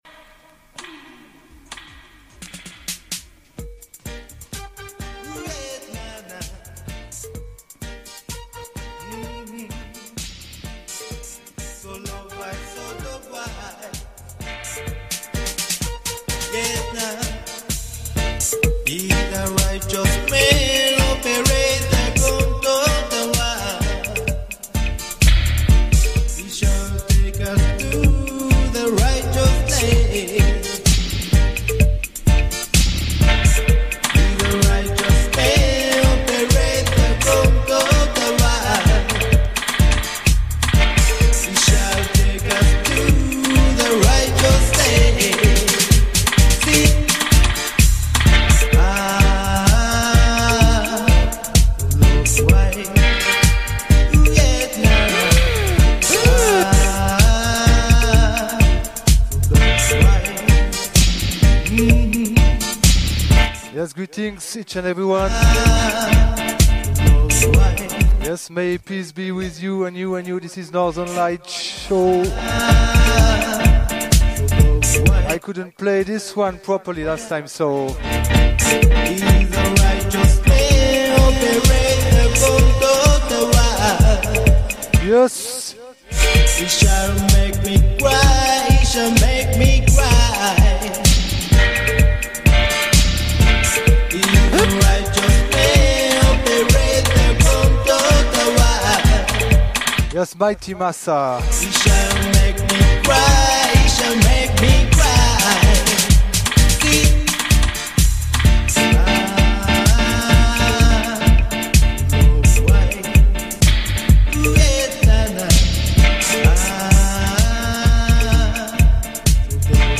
various Roots & Dubz selection this week_